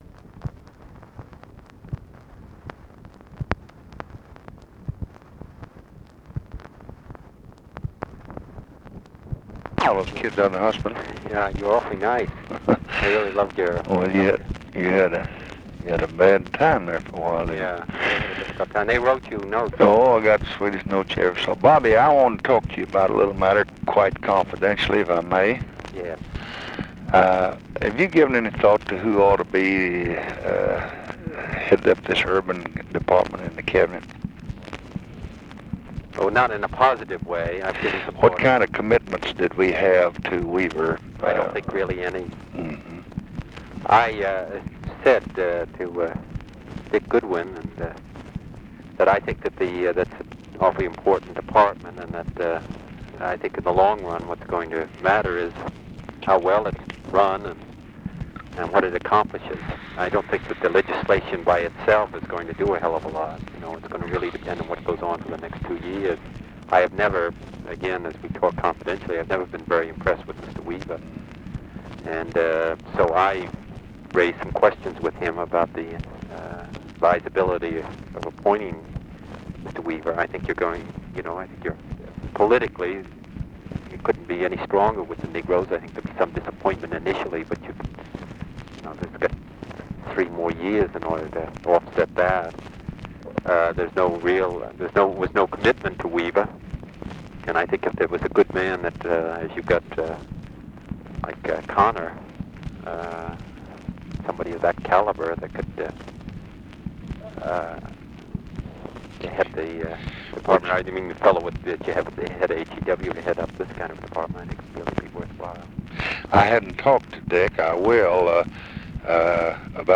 Conversation with ROBERT KENNEDY, September 13, 1965
Secret White House Tapes